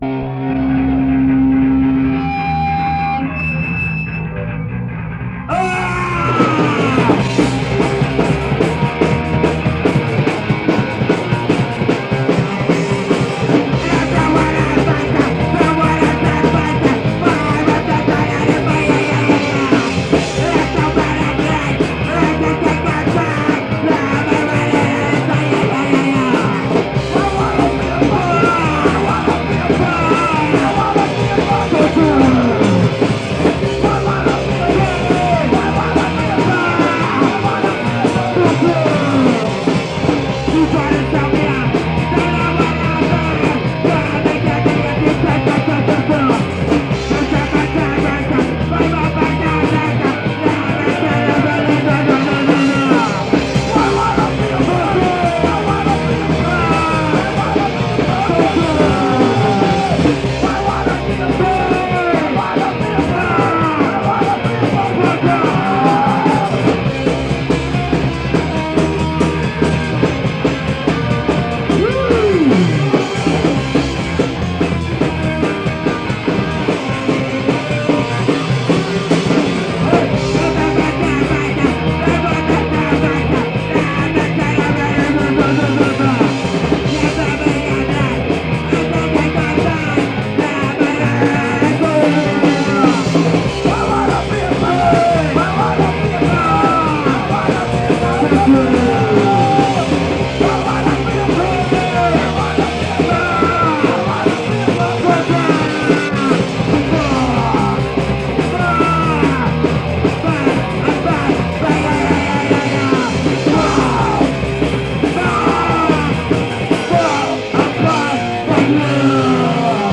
Nagrań dokonano za pomocą decka i 2 mikrofonów.